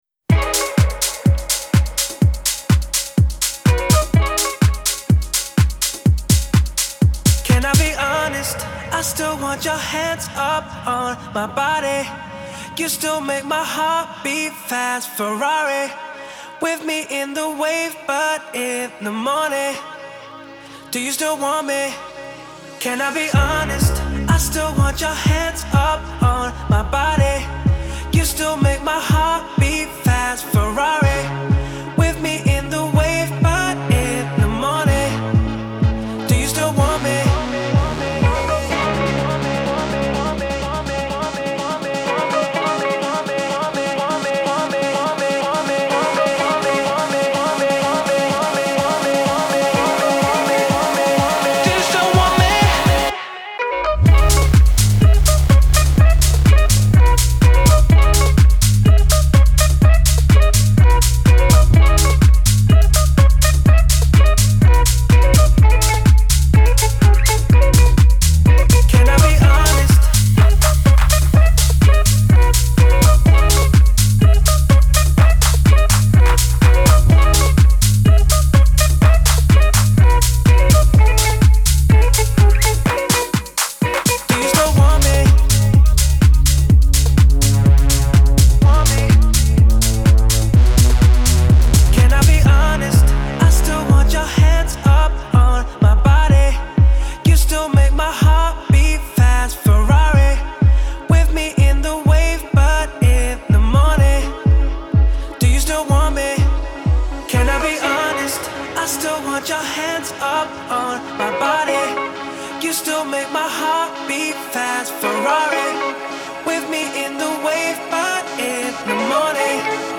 Genre : Electro, Alternative